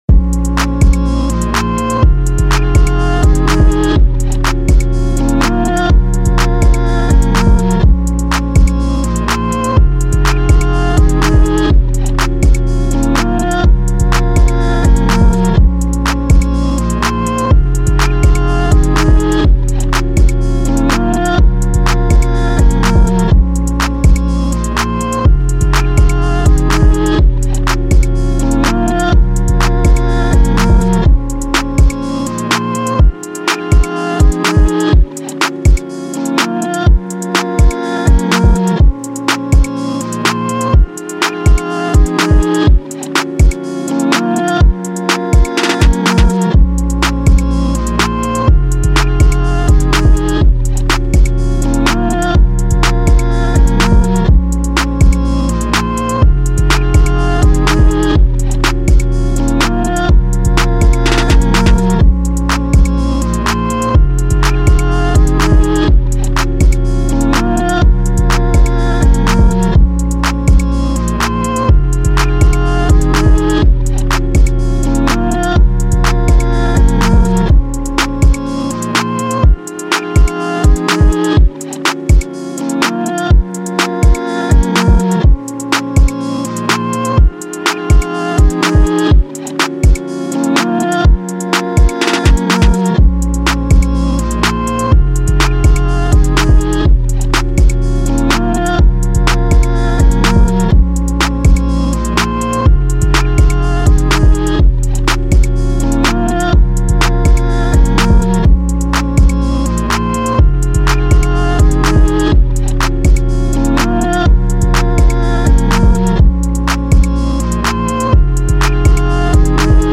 2024 in Hip-Hop Instrumentals , Official Instrumentals